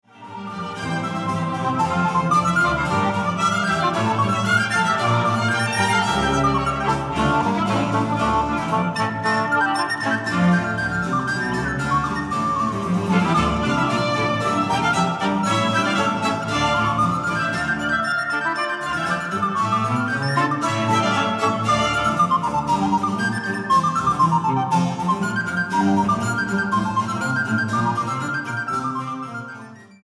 Concierto para flauta de pico y continuo